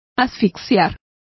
Complete with pronunciation of the translation of asphyxiate.